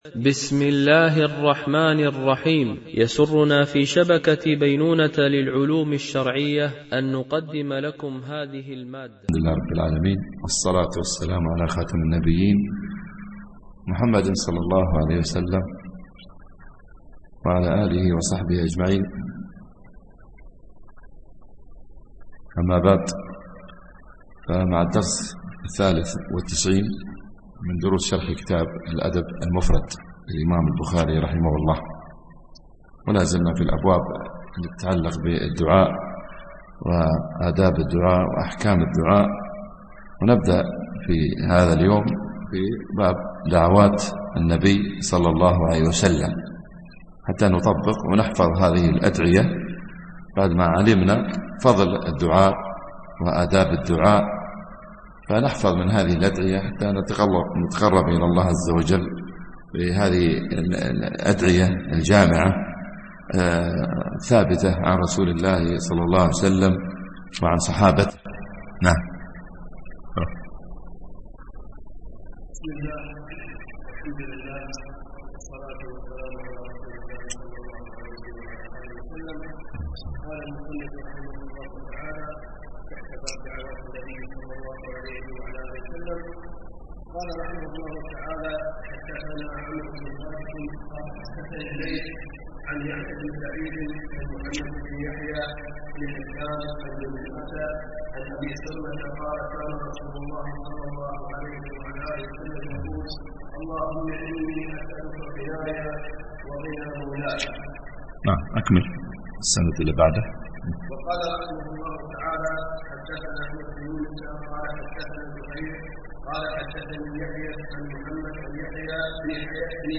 شرح الأدب المفرد للبخاري ـ الدرس 93 ( الحديث 662 – 670 )